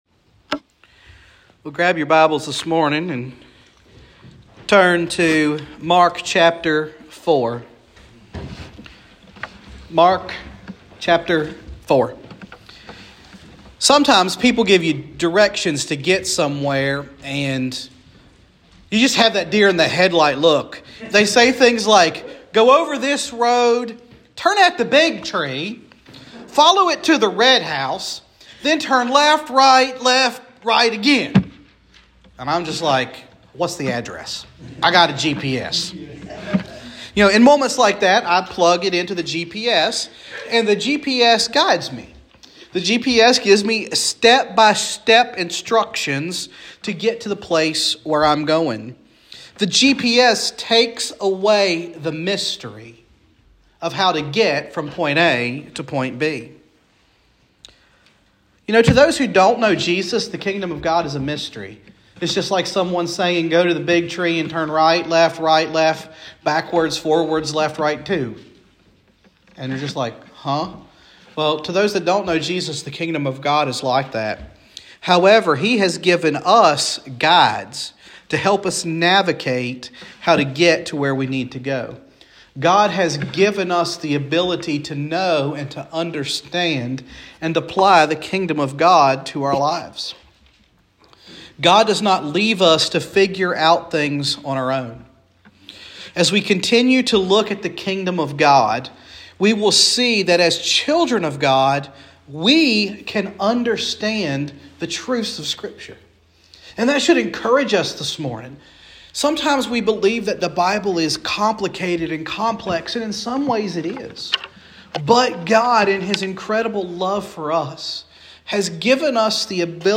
Sermons | Hopewell First Baptist Church